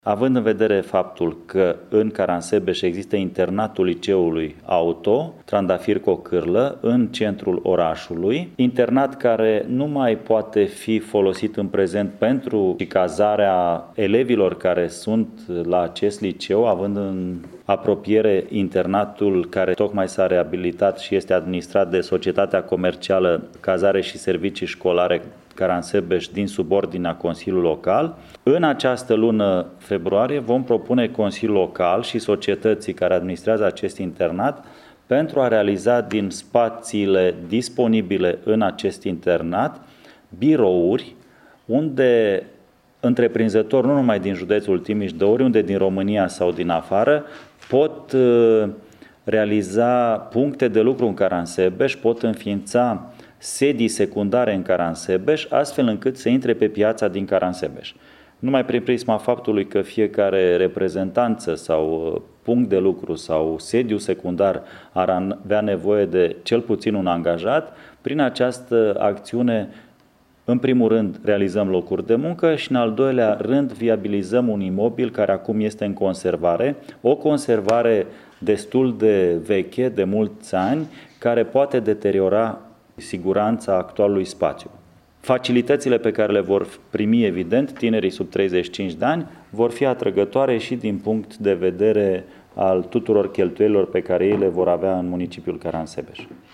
Primarul Marcel Vela: